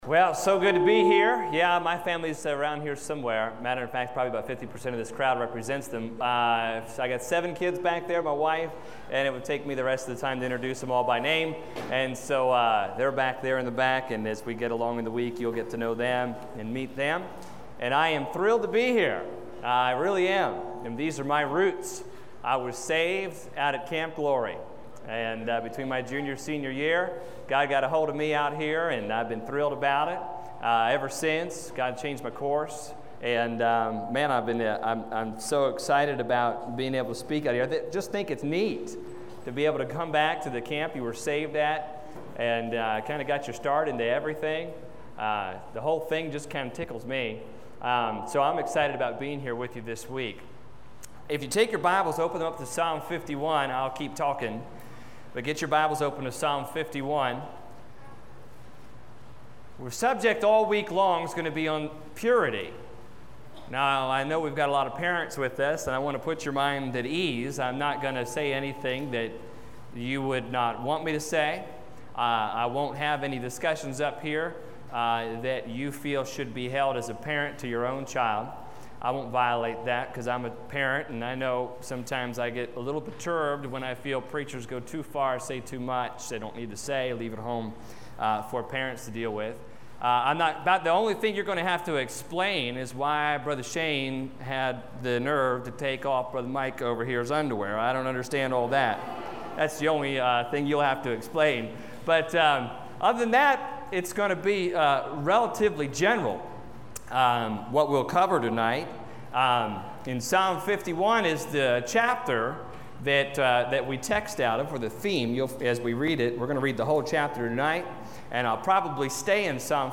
Listen to Message
Service Type: Teen Camp